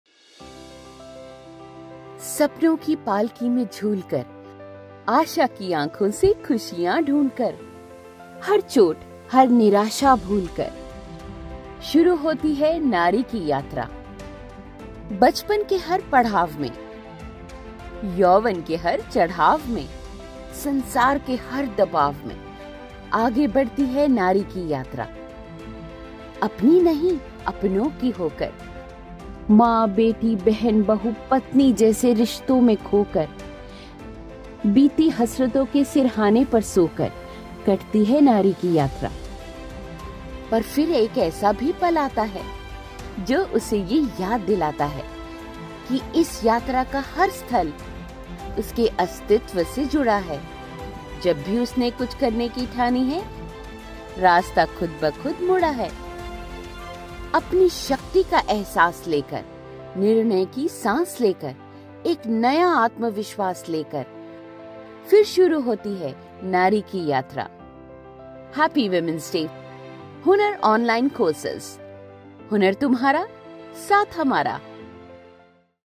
English (Indian)
Impersonations
Home Studio Equipment
Mic : Shure SM58
HighSoprano